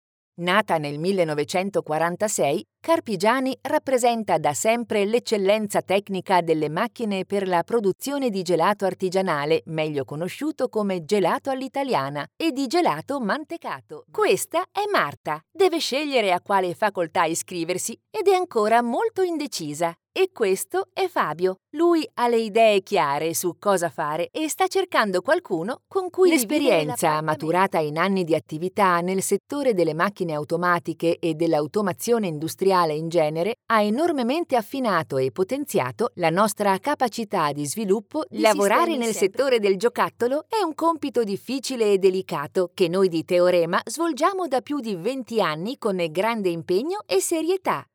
Italian female voice talent, Experienced, versatile, friendly, educated, assured.Warm and clear for narration, more young for commercial, professional and smooth for presentation
Sprechprobe: Industrie (Muttersprache):